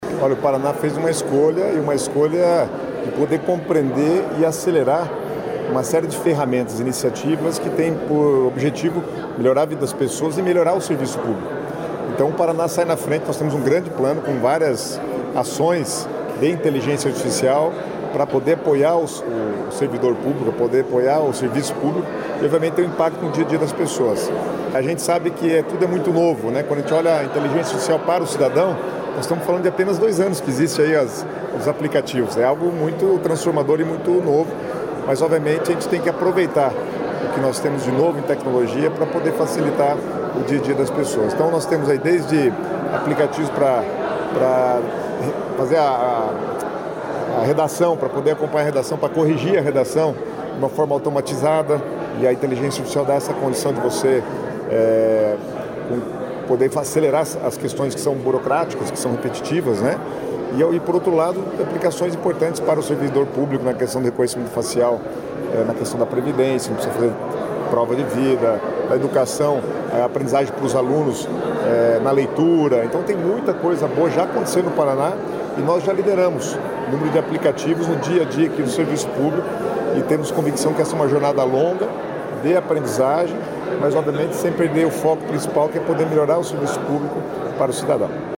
Sonora do secretário das Cidades, Guto Silva, sobre o lançamento do pacote de soluções de Inteligência Artificial